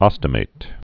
(ŏstə-māt)